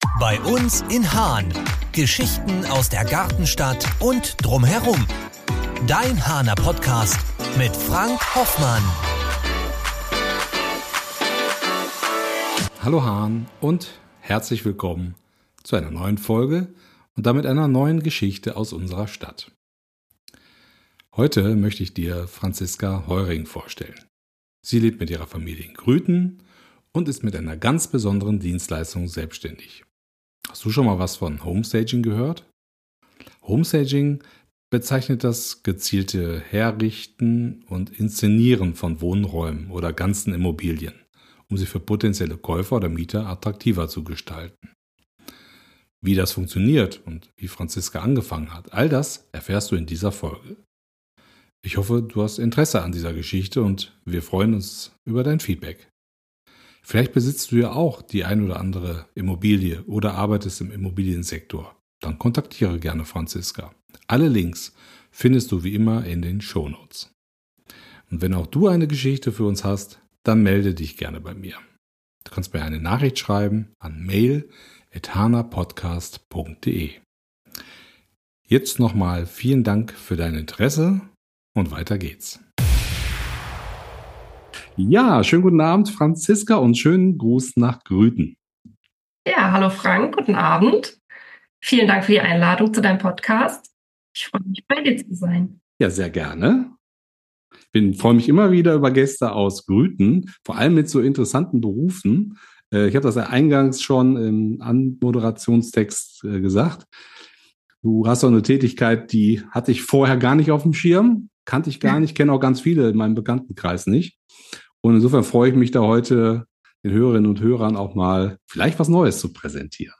Was das ist und wie Du vielleicht davon profitieren kannst, erfährst Du in diesem Gespräch.